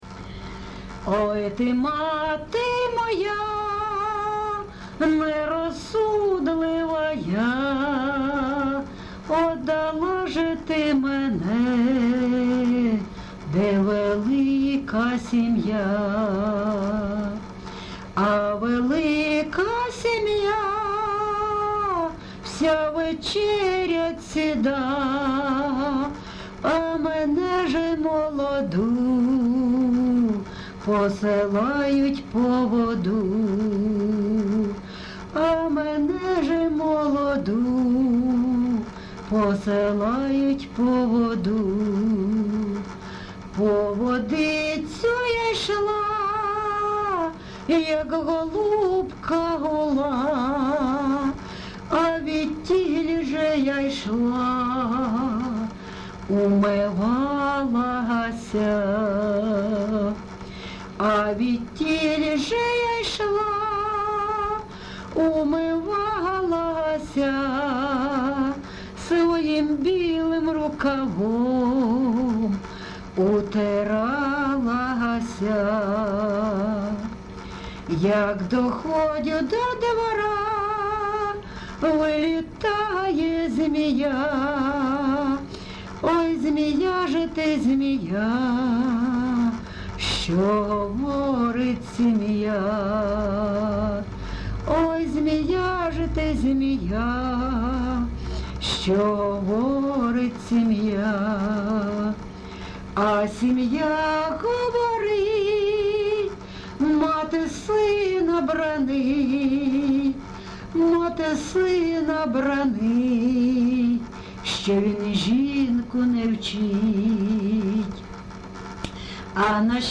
ЖанрПісні з особистого та родинного життя
Місце записус. Лозовівка, Старобільський район, Луганська обл., Україна, Слобожанщина